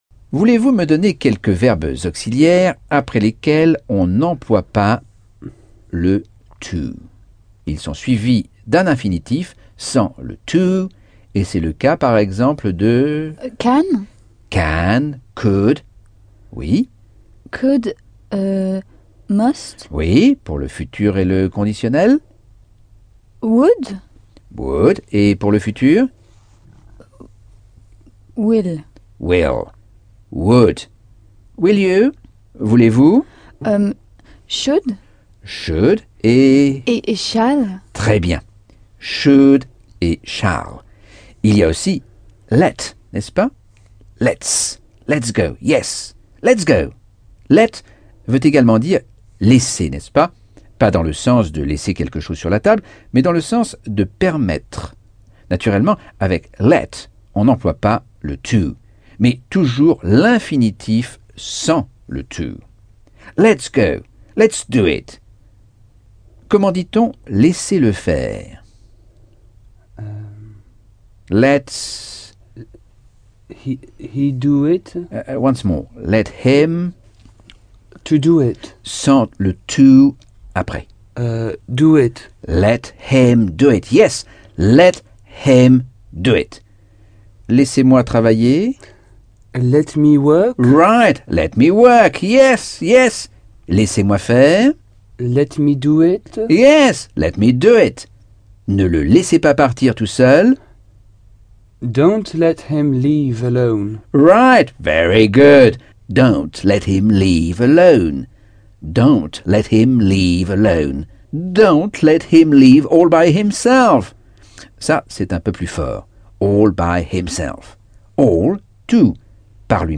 Leçon 11 - Cours audio Anglais par Michel Thomas - Chapitre 11